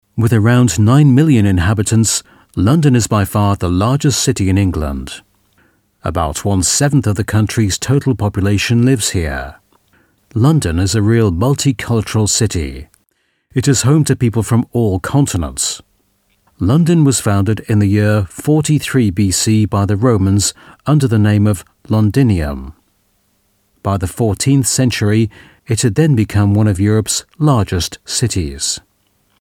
Native Speaker
Explainer Videos